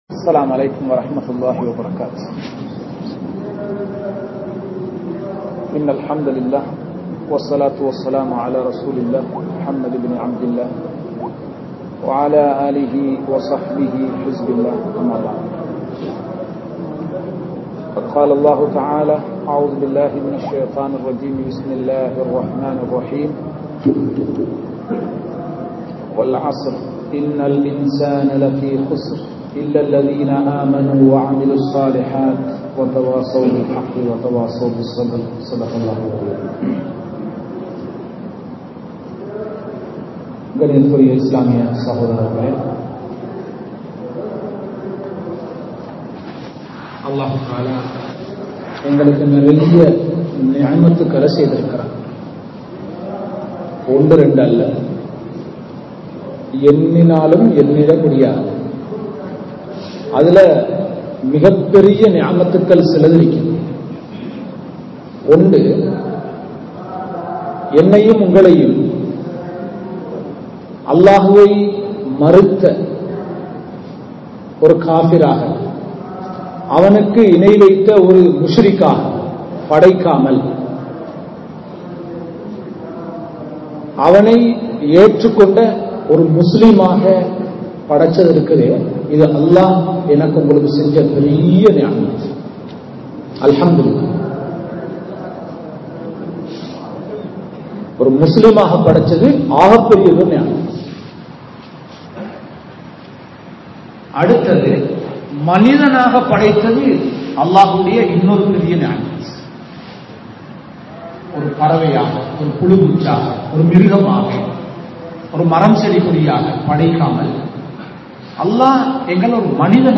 Allah`vai Maranthulla Muslimkal (அல்லாஹ்வை மறந்துள்ள முஸ்லிம்கள்) | Audio Bayans | All Ceylon Muslim Youth Community | Addalaichenai